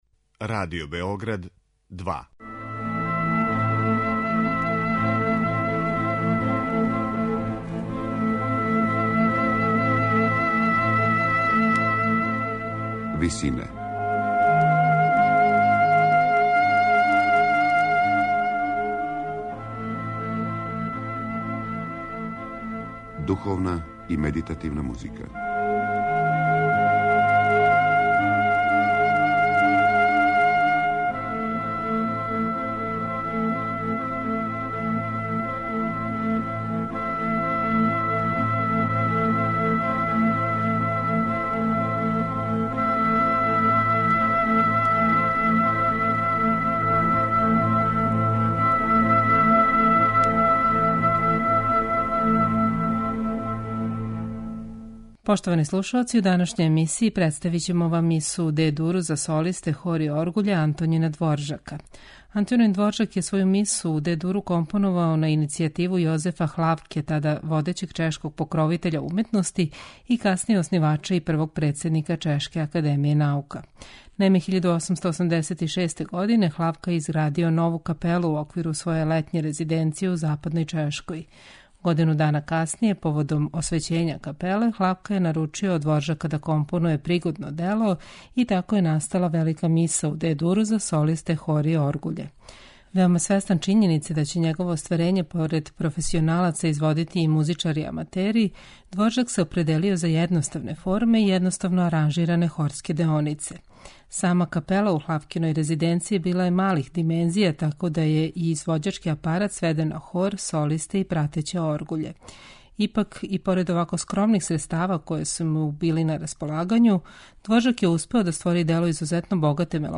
хора
оргуљаша